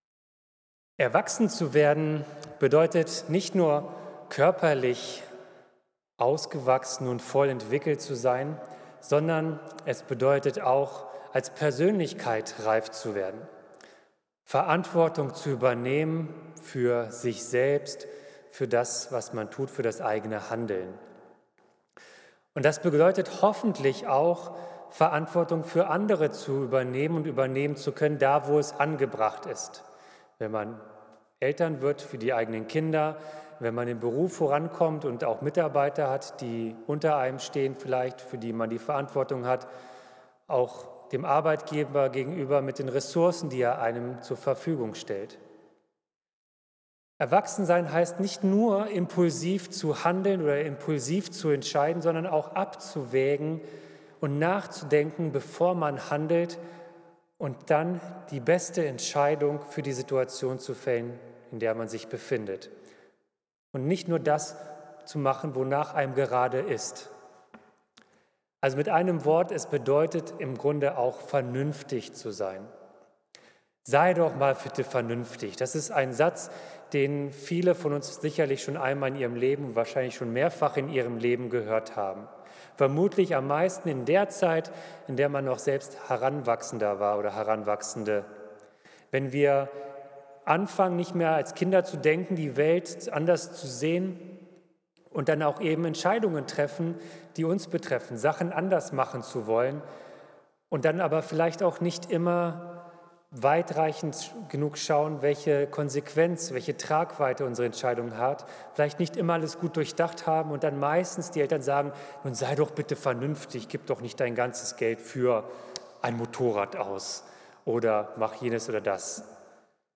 Predigt zu Matthäus 18, 12-14 - Ist Gott vernünftig?